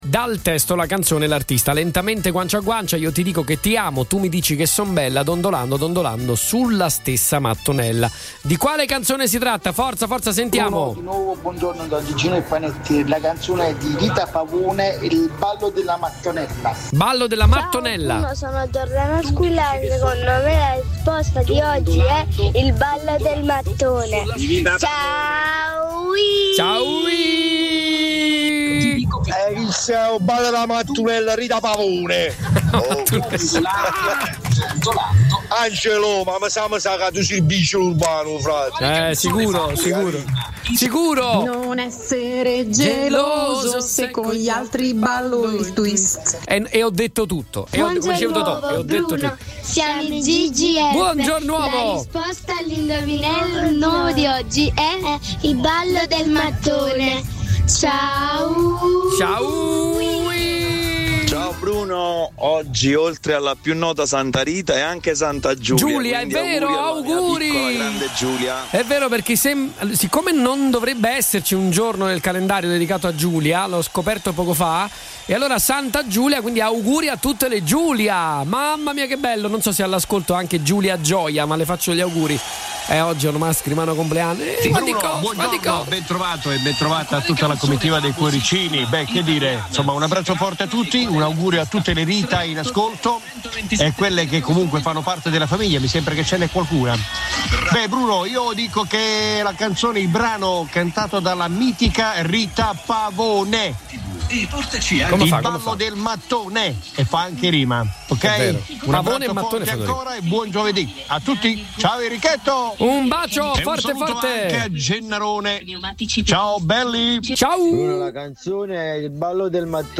I cuoricini di Radio Punto Nuovo non si sono fatti attendere: ecco i messaggi vocali con le risposte sono arrivati a raffica!